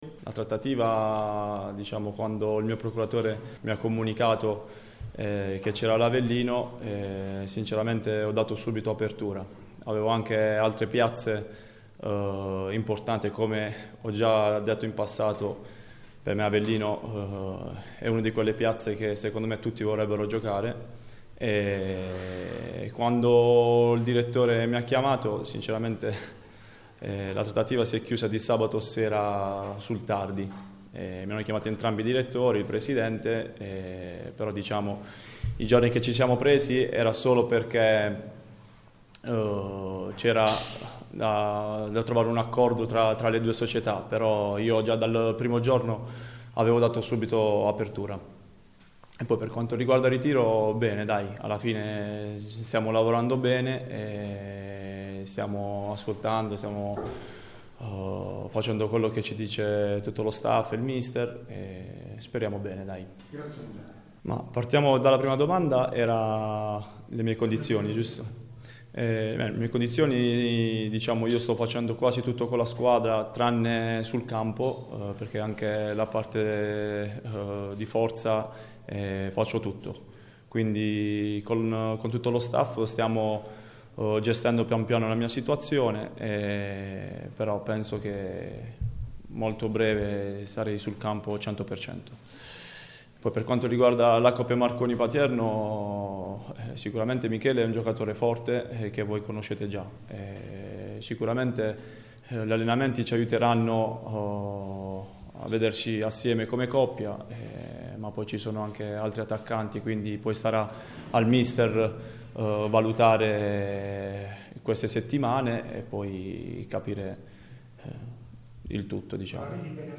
Conferenza stampa